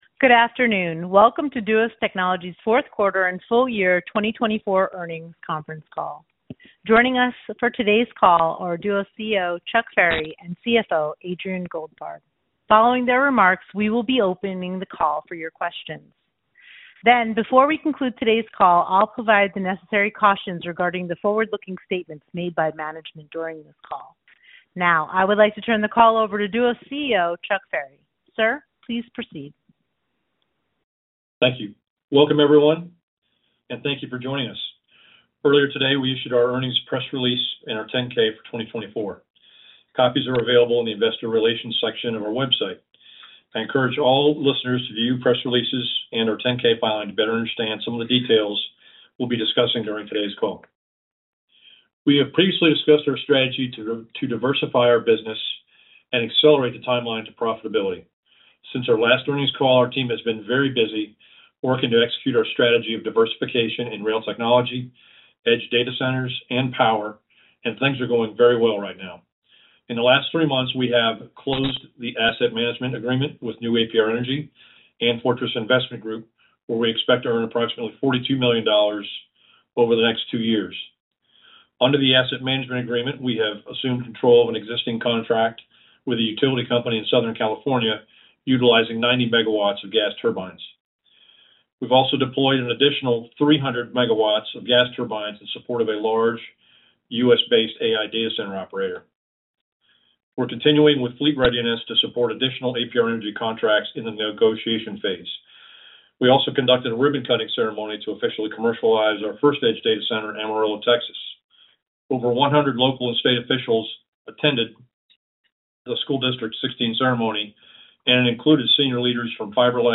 Fourth Quarter 2024 Financial Results Conference Call